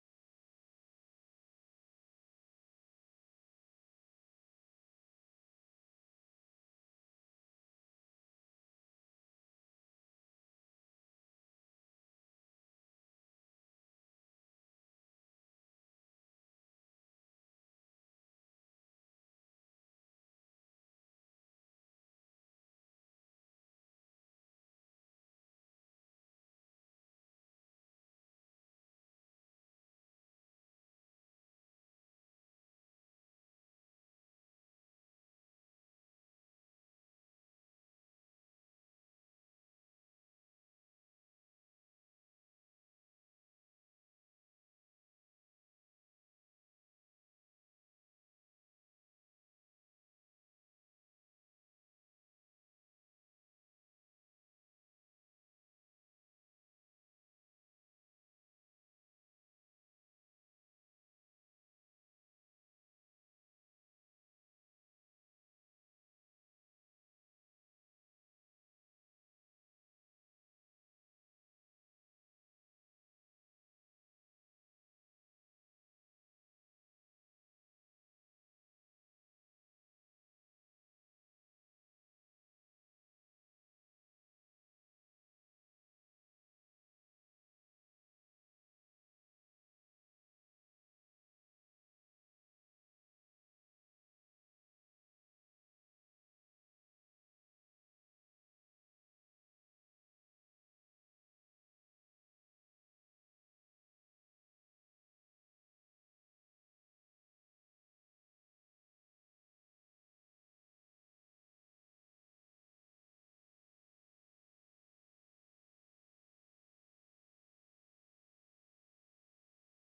Есть вот такое ВИА  (какчество - сами понимаете ...)
А, может, просто качество подводит восприятие.